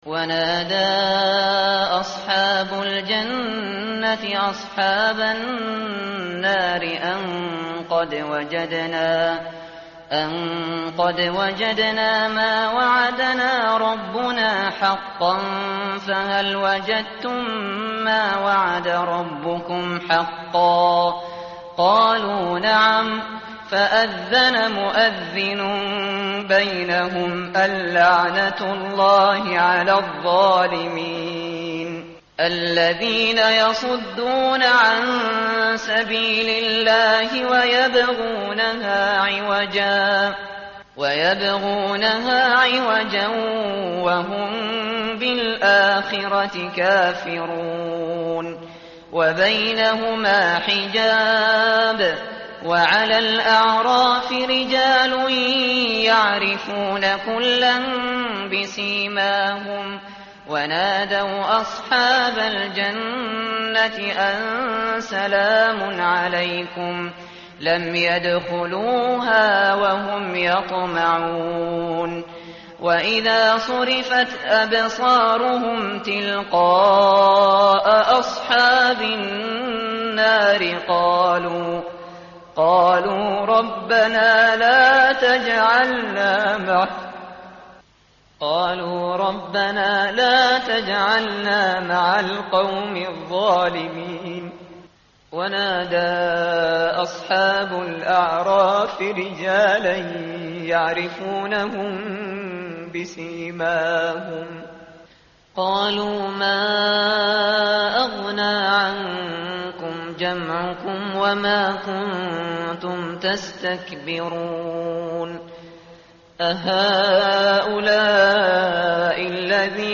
tartil_shateri_page_156.mp3